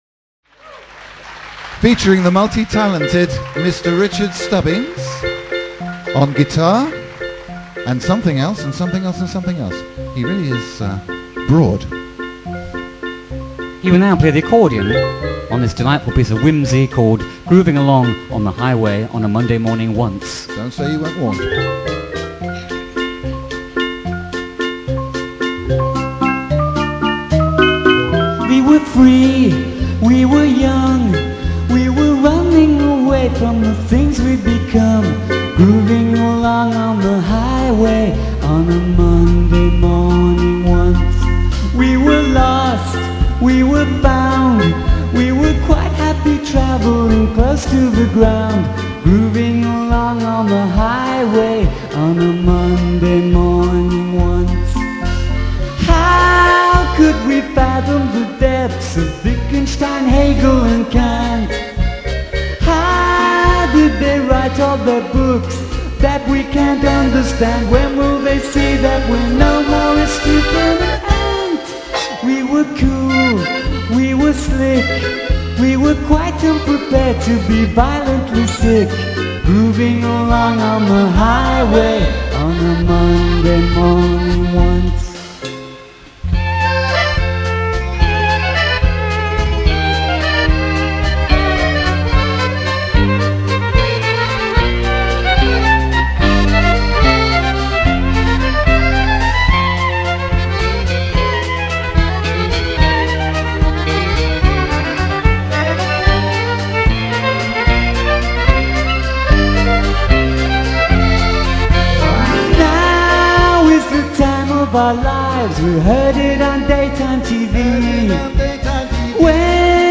*NEW* Excellent quality mp3 files of the new live band!
The Riding's Arts Centre on June 12th, 1999